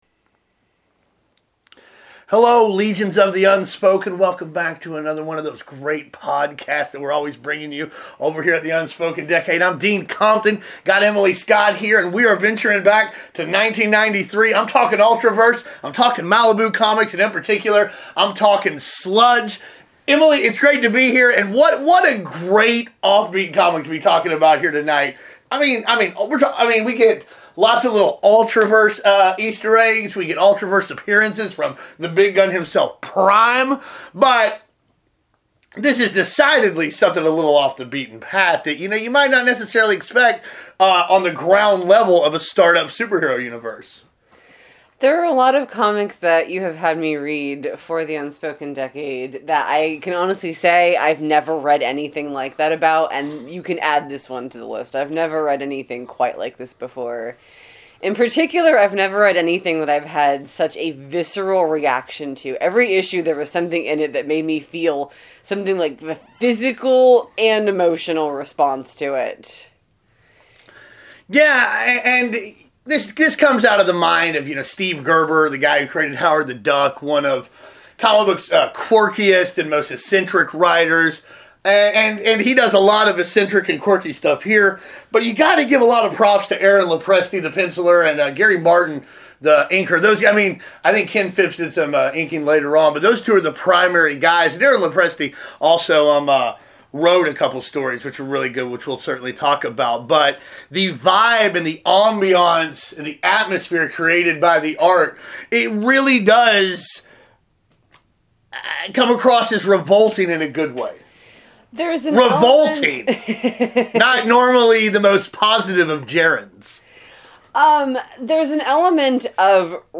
sat down and had a nice chat about Malibu’s muck monster below NYC!